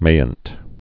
(māənt, mānt)